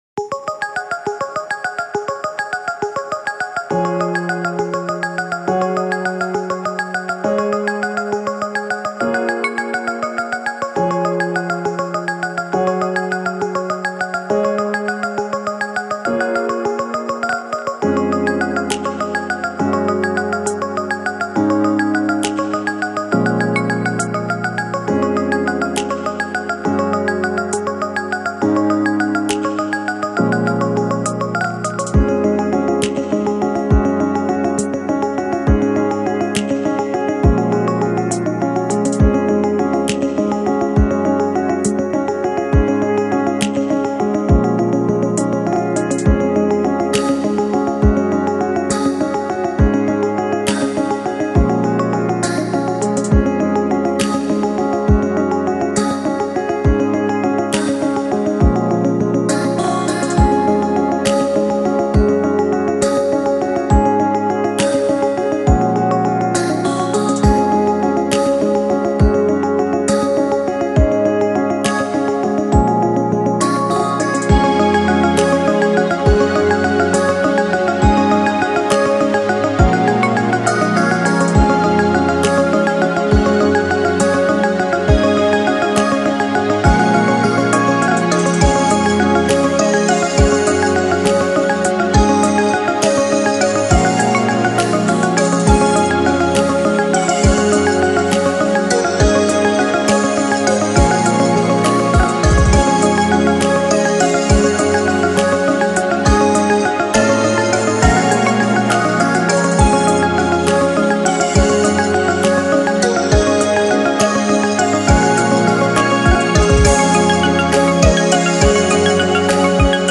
ジャンルチップチューン
BPM１０２
使用楽器やわらかい音のシンセサイザー、ボイス
儚いイメージはそのままに、テンポを上げてLo-fi気味に編曲いたしました。
エレクトロニック(Electronic)